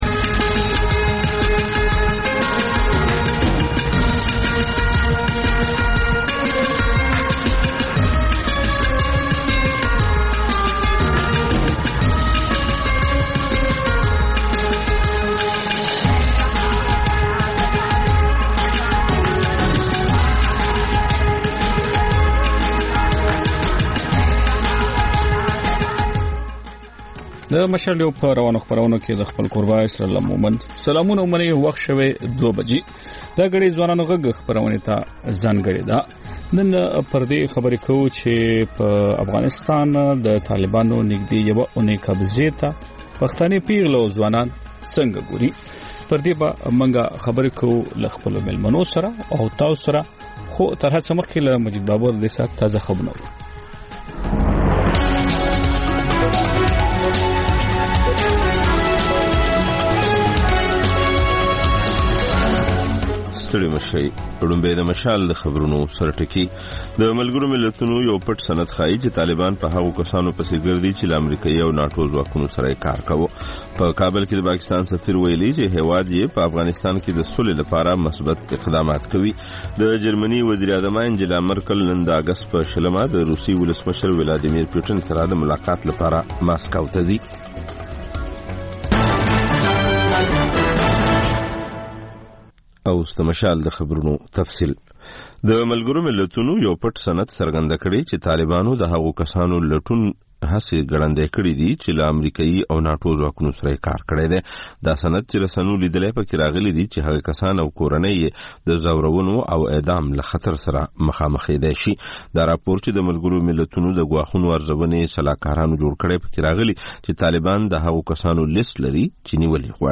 د مشال راډیو مازیګرنۍ خپرونه. د خپرونې پیل له خبرونو کېږي، بیا ورپسې رپورټونه خپرېږي.